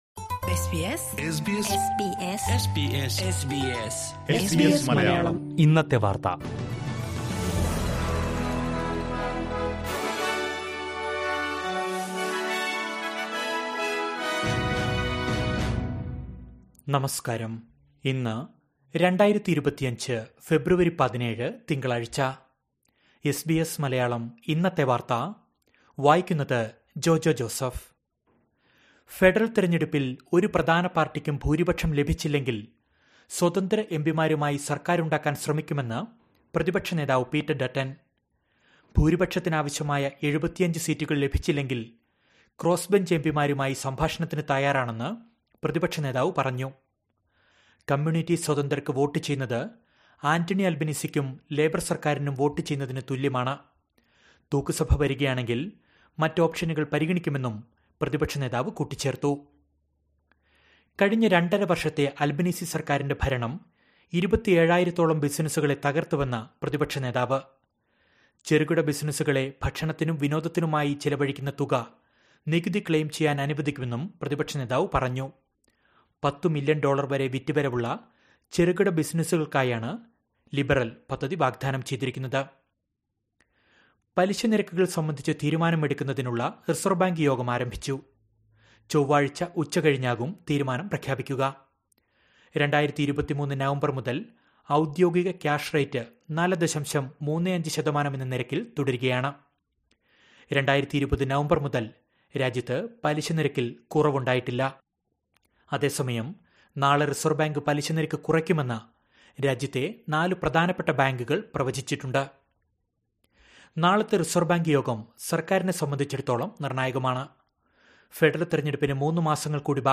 2025 ഫെബ്രുവരി 17ലെ ഓസ്‌ട്രേലിയയിലെ ഏറ്റവും പ്രധാന വാര്‍ത്തകള്‍ കേള്‍ക്കാം...